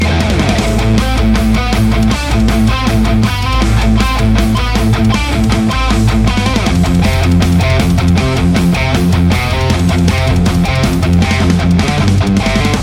Very simple to get great tones, no switching or options just raw jaw dropping distortion tone.
Metal Riff Mix
RAW AUDIO CLIPS ONLY, NO POST-PROCESSING EFFECTS
Hi-Gain